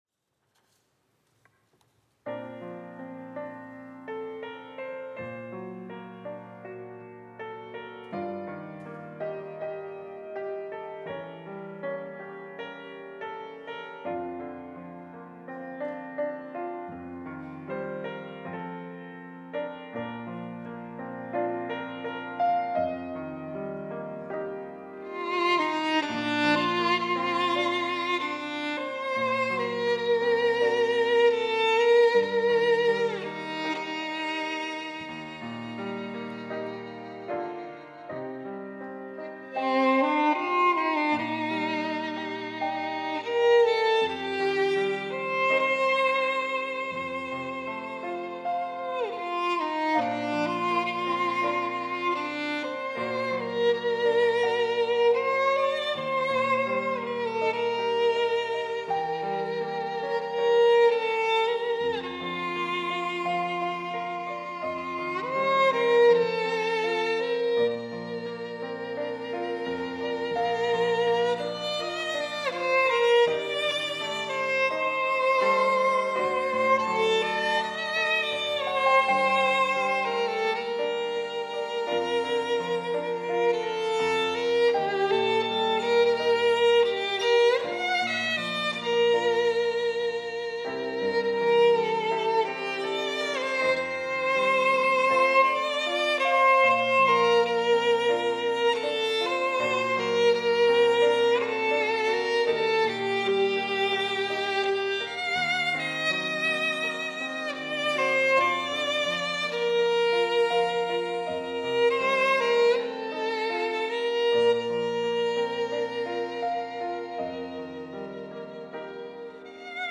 특송과 특주 - 축복하노라